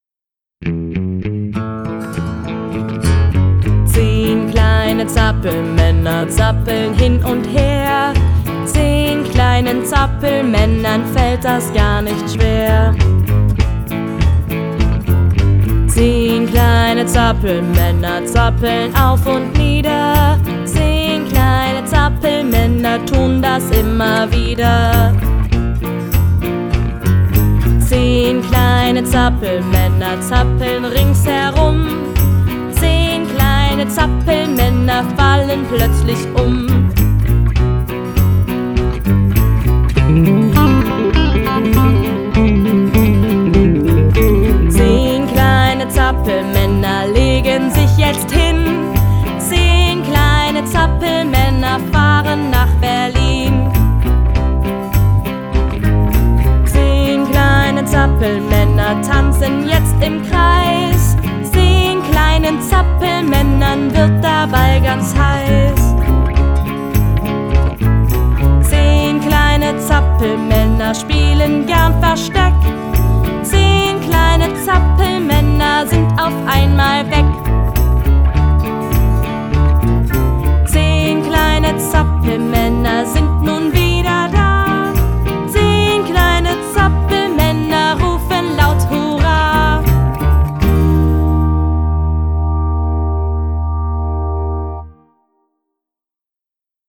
Kinderparty